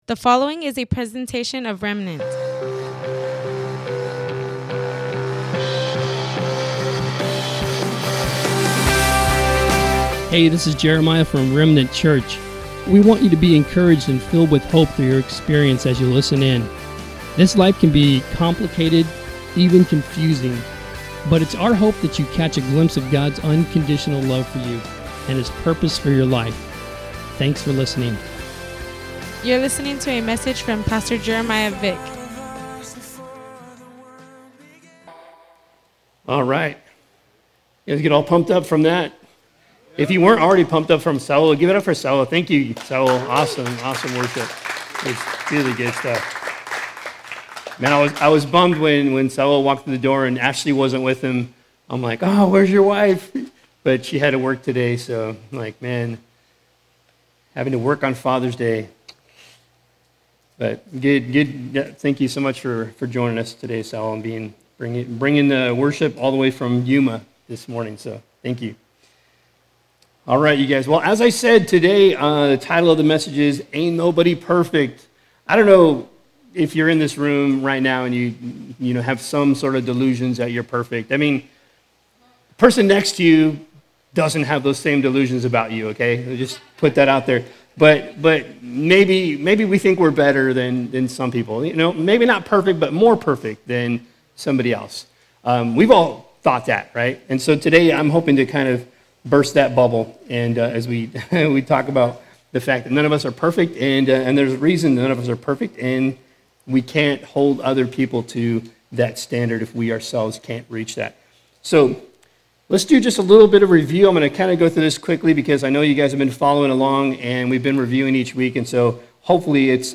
Happy Father’s Day and welcome to the livestream of our worship gathering at Remnant Church in Imperial Valley, CA. Today